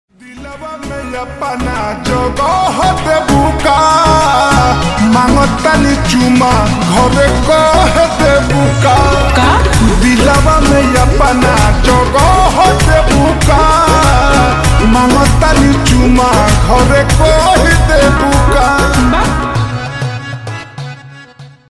Bhojpuri Song
(Slowed + Reverb)